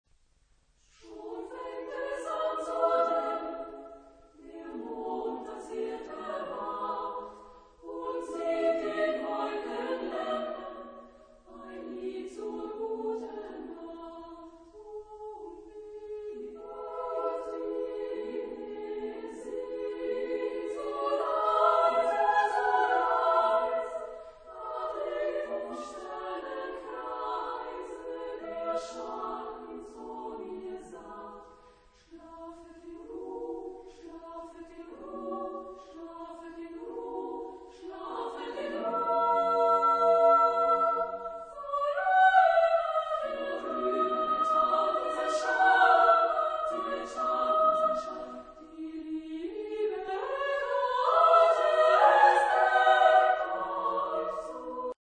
in Weltliche Musik für Frauenchor a cappella
Genre-Style-Forme : Chœur ; Chanson poétique
Caractère de la pièce : andantino ; expressif
Type de choeur : SSAA  (4 voix égales de femmes )
Tonalité : mi majeur